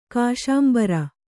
♪ kāśāmbara